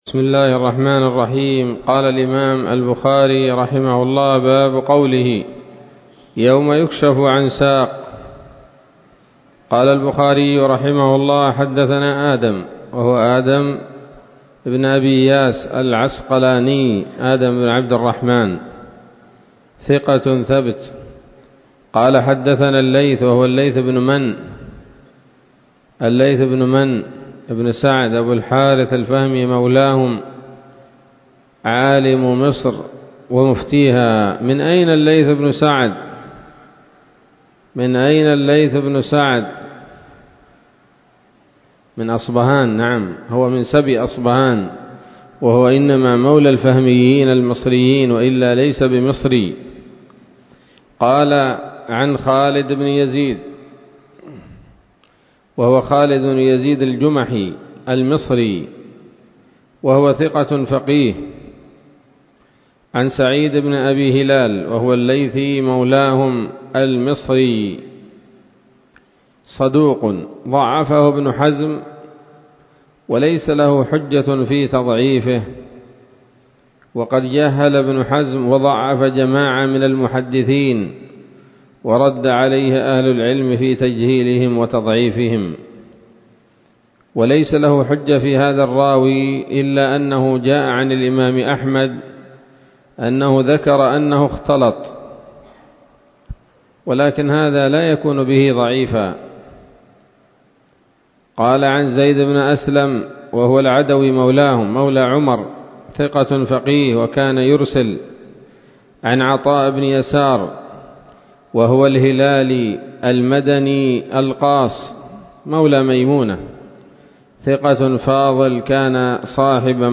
الدرس الثاني والسبعون بعد المائتين من كتاب التفسير من صحيح الإمام البخاري